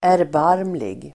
Ladda ner uttalet
Uttal: [ärbar:mlig]